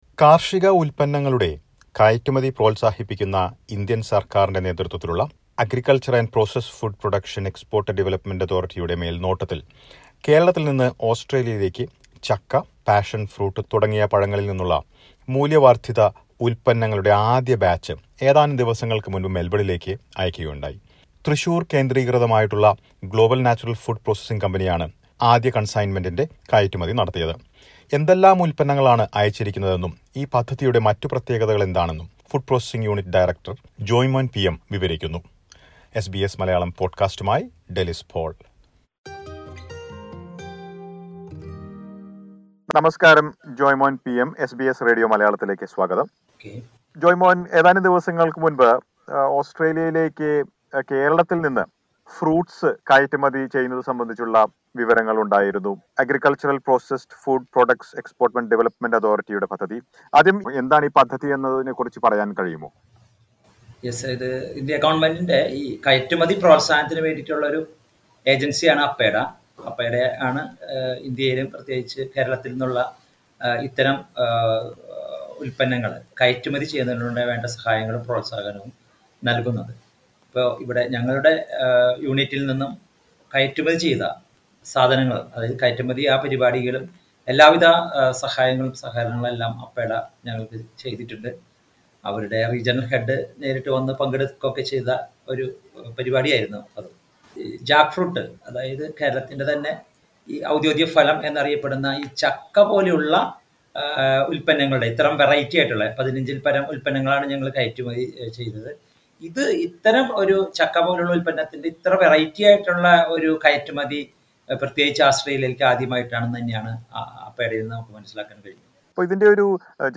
The first batch of value-added products derived from various fruits in Kerala including Jackfruit, Passion fruit etc were sent to Melbourne recently. Listen to a report.